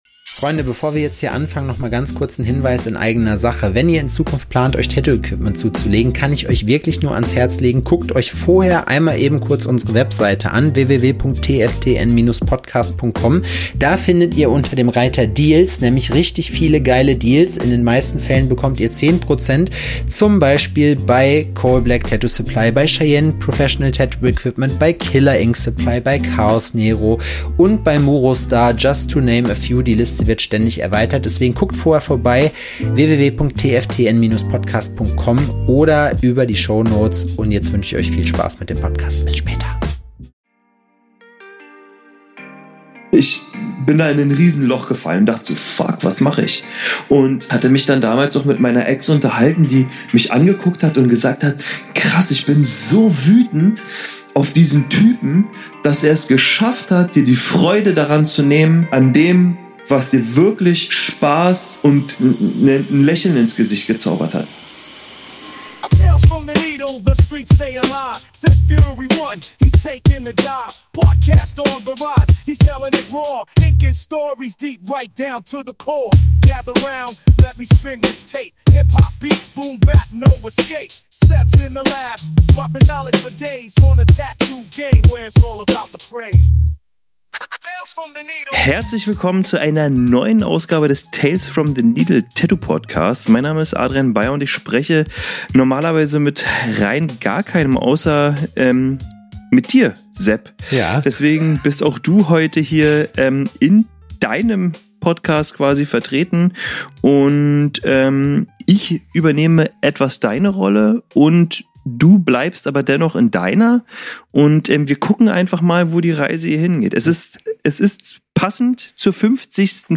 (Intro/Outro-Track Made With Suno AI) [ WERBUNG ] Bei unseren Werbepartnern könnt ihr richtig sparen - unter Anderem 10% auf eure erste Bestellung bei Killer Ink, Cheyenne Tattoo Equipment oder CoalBlack!